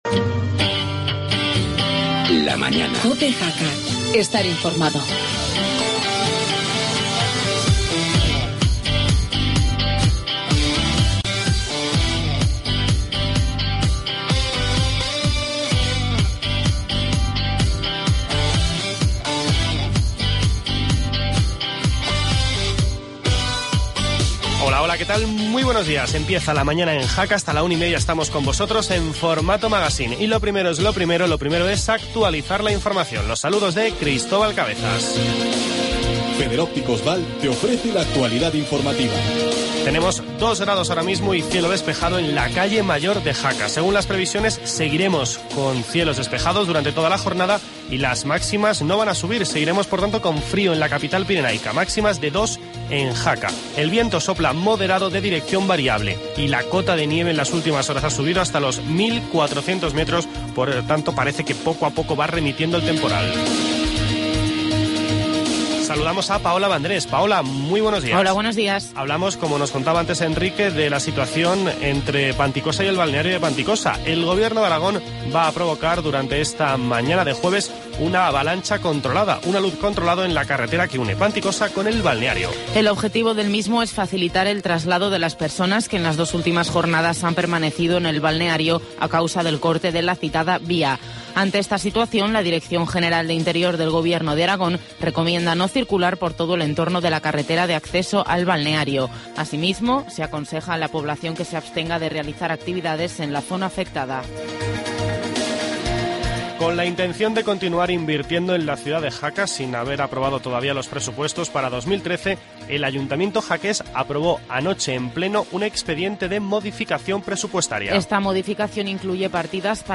Hablamos con Elena Allué, directora general de Turismo de Aragón.